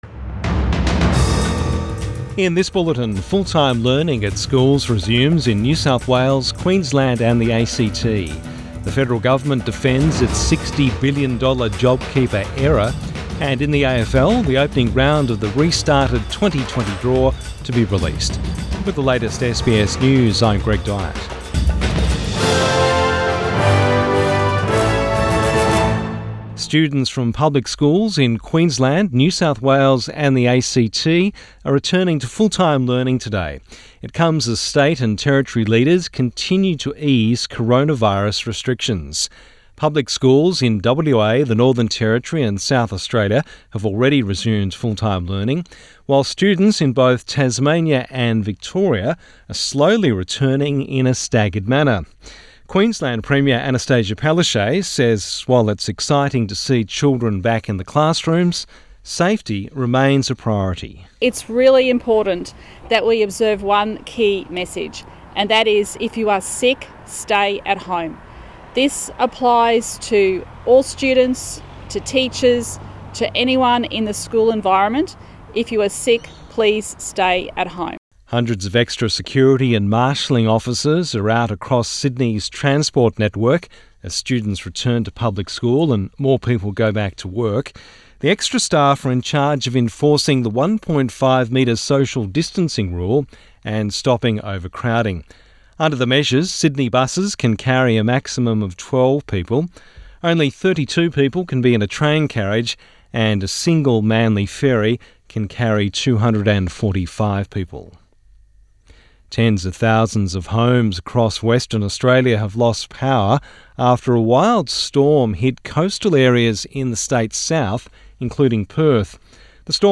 Midday bulletin 25 May 2020